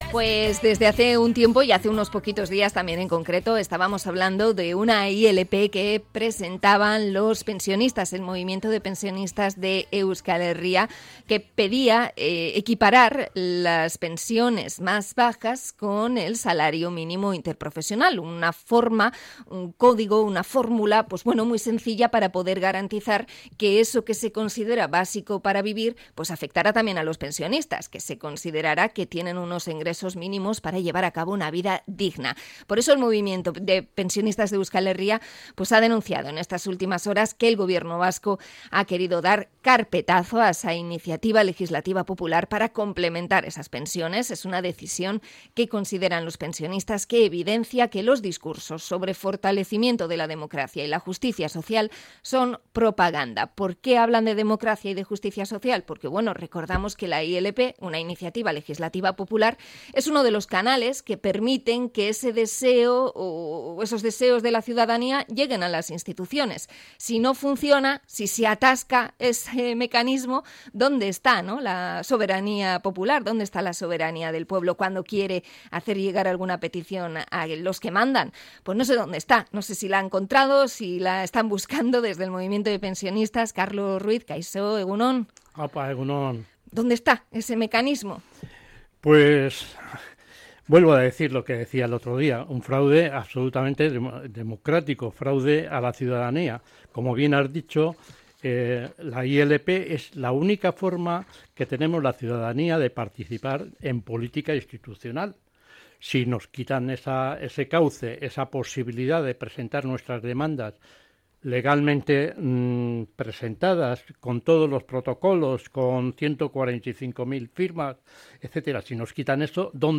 Entrevista a los pensionistas de Euskal Herria por el criterio desfavorable del Gobierno Vasco